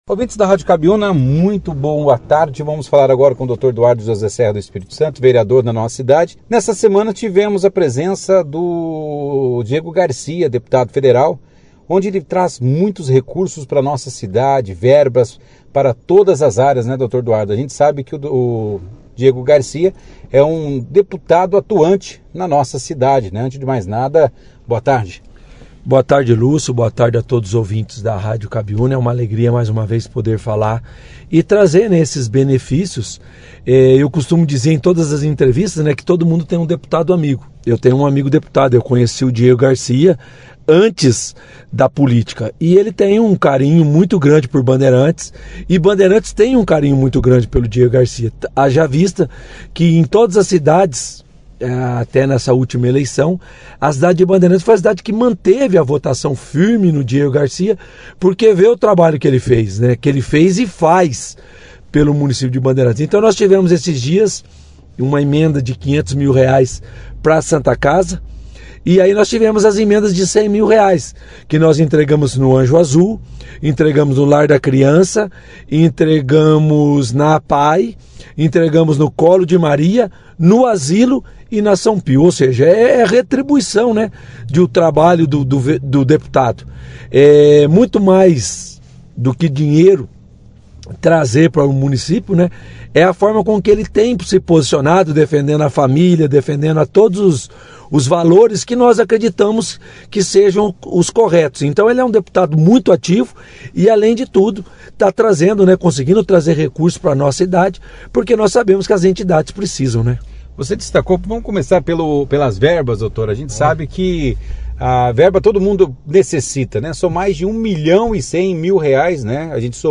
O vereador Dr. Eduardo José Serra, participou da edição deste sábado, 17/06, do jornal “Operação Cidade” para destacar o importante trabalho realizado pelo deputado Diego Garcia, que tem destinado emendas para diversas entidades beneficentes em Bandeirantes. Durante a entrevista, o vereador ressaltou o impacto positivo dessas emendas nas instituições Apae, São Pio, Colo de Maria, Santa Casa, Anjo Azul e Asilo São Vicente de Paula.